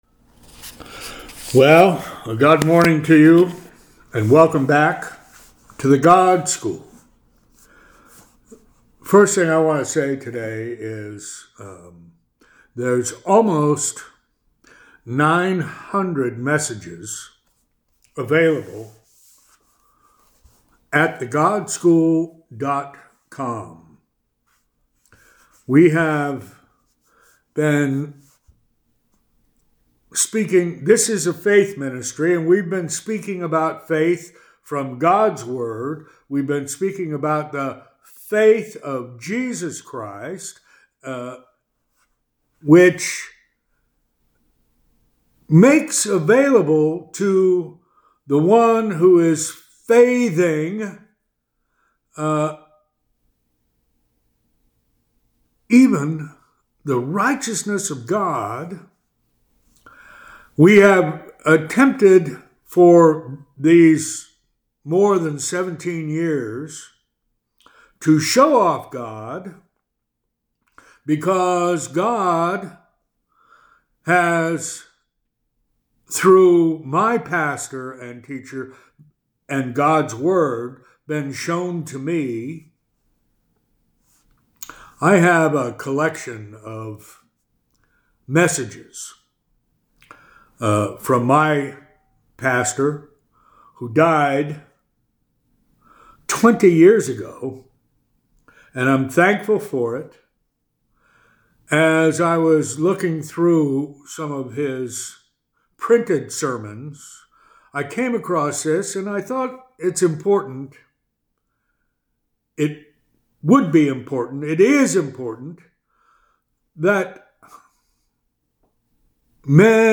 Weekly Teaching